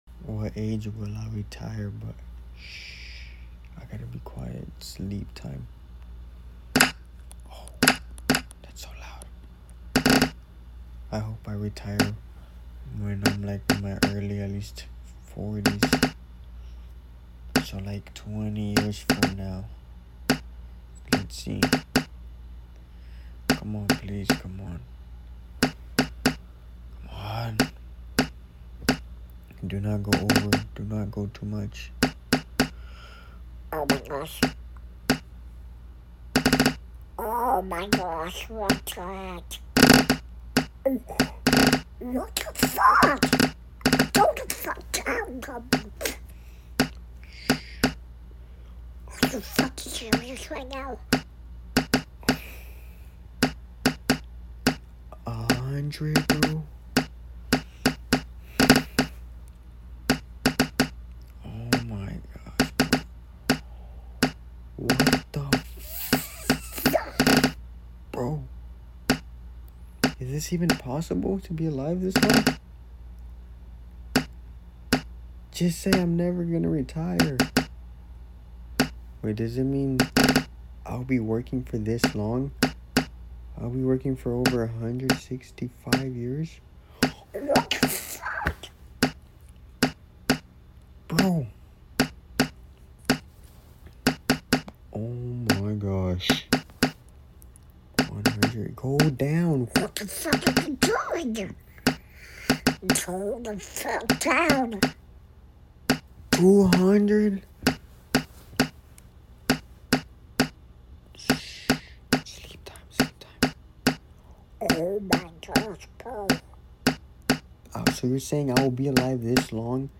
wow sound effects free download